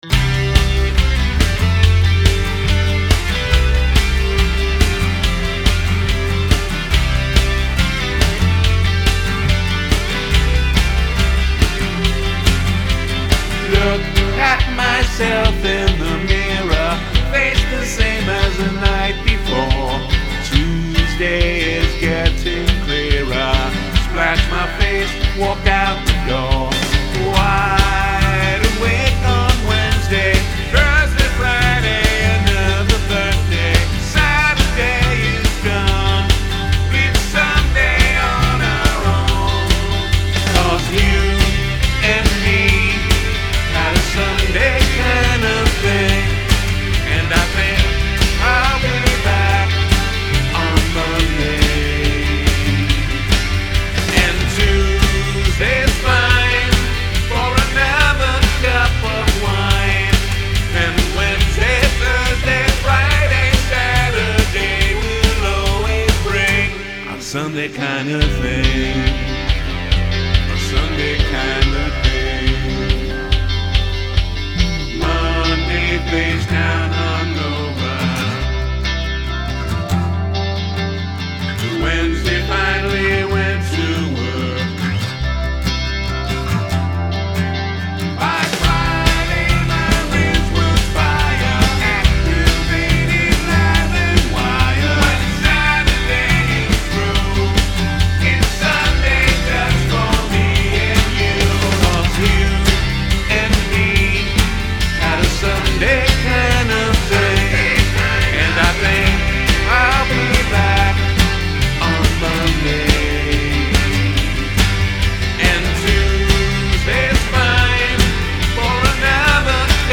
Teeny Pop